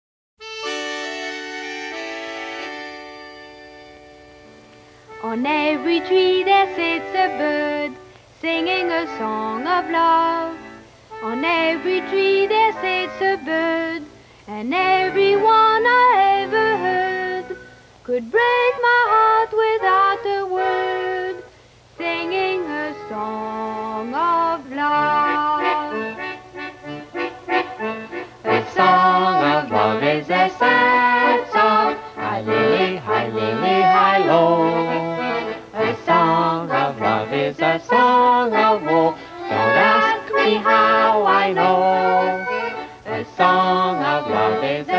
A kind of carnival waltz
in the best-possible monaural sound.